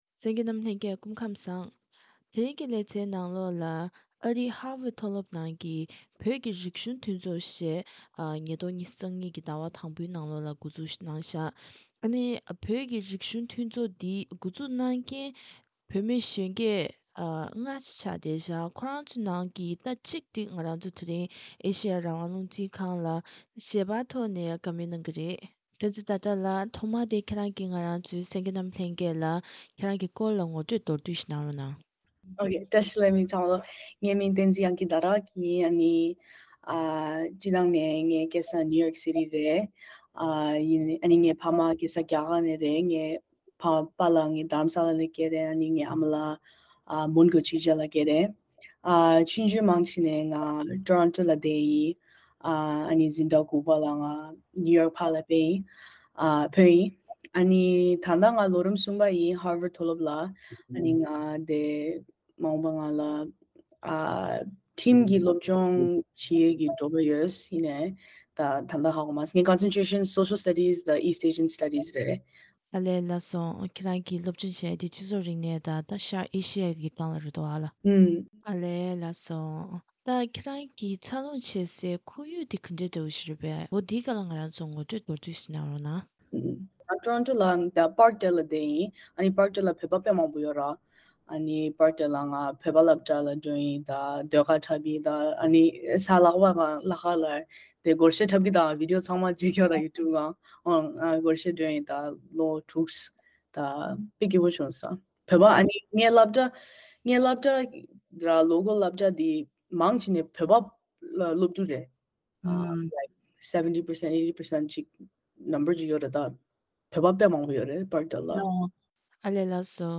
ཞལ་པར་བརྒྱུད་ནས་བཀའ་དྲི་ཞུས་པ་ཞིག་གསན་རོགས་